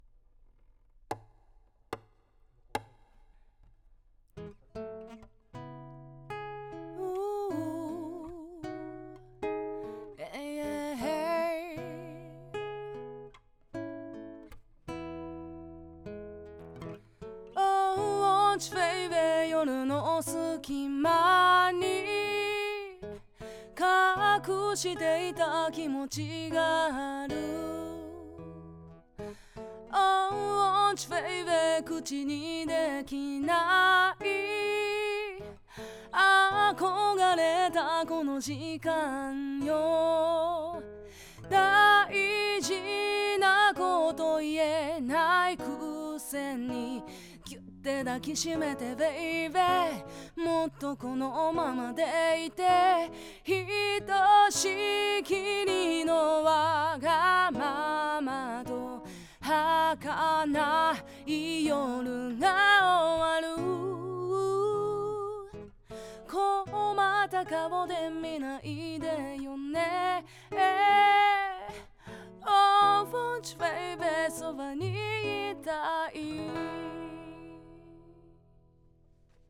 軽くAIRのリバーブをかけただけで、ほかのエフェクトはEQを含め一切使っていない
ここでは24bit/96kHzでレコーディングしてみたのですが、その結果が以下のものです。
U87Aiでのレコーディング結果　（うまくダウンできない場合は
使っているのはいずれもM-TRACK 2x2M内蔵のマイクプリアンプのみで、EQもかけてないし、使っているのはリバーブのみ。よ～く聴き比べているとU87Aiのほうが高い音をより捉えている感じがしますが、6,980円でここまでの性能を出せれば十分だと思いませんか？
U87Ai.wav